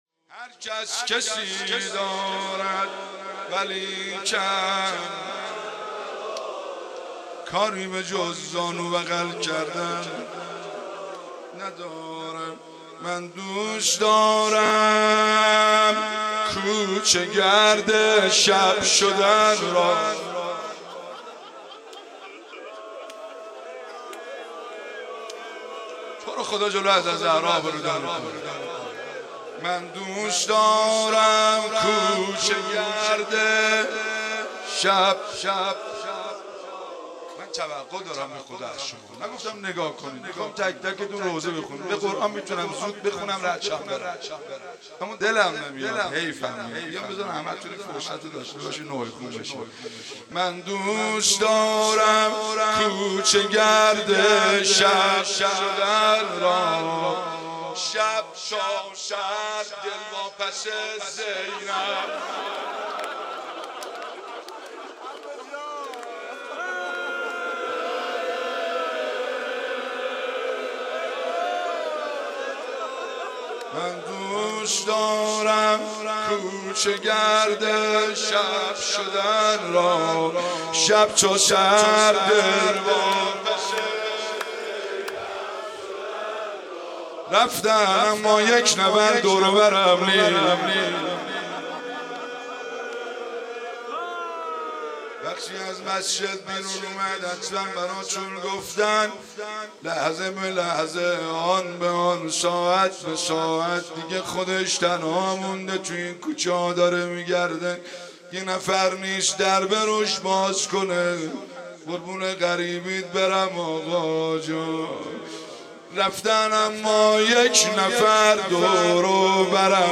شب اول محرم 96 - هیئت فاطمیون - روضه